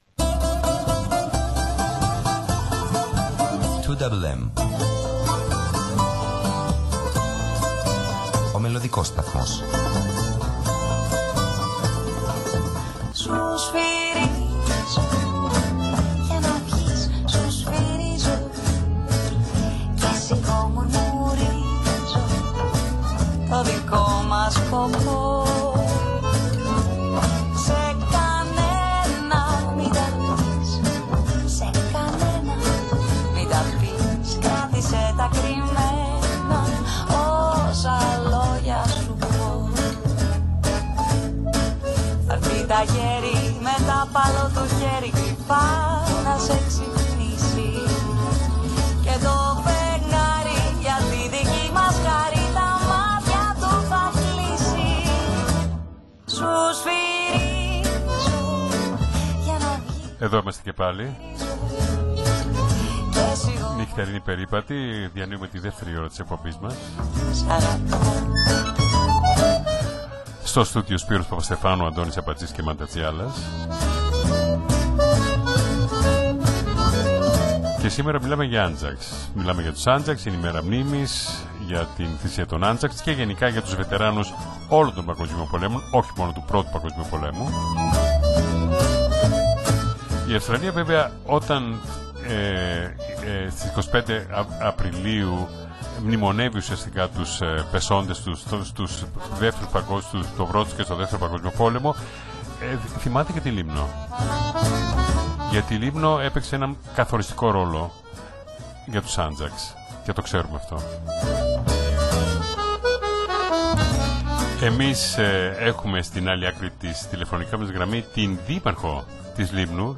Η Δήμαρχος της Λήμνου, Ελεονώρα Γεώργα, αναφέρεται στις εκδηλώσεις μνήμης για τους Αnzacs, δηλώνοντας ότι η παρουσία τους αποτελεί ιστορία του νησιού. Περισσότερα ακούμε από την Δήμαρχο της Λήμνου Ελεονώρα Γεωργά στην συνέντευξη που παραχώρησε
Eleonora-Georga-Mayor-of-Lymnos.mp3